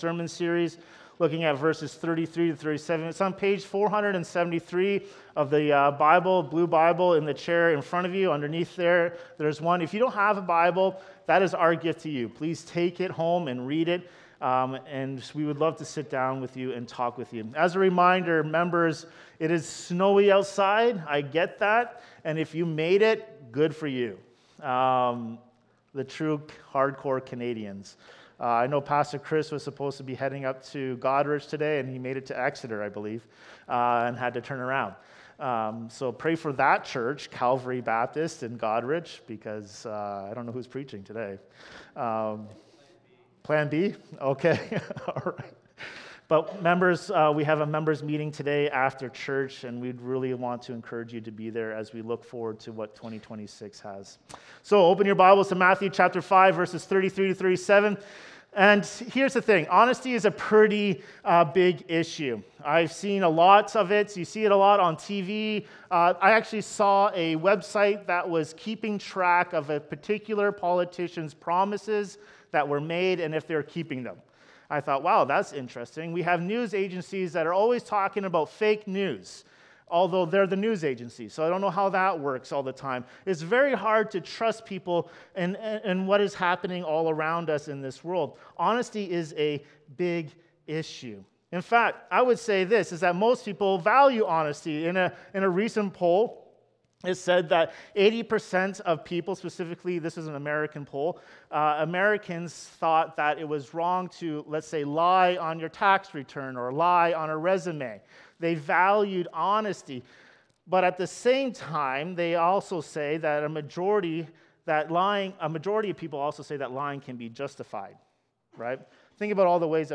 This sermon explores honesty, oaths, gospel grace, and how Christ transforms us into truthful witnesses.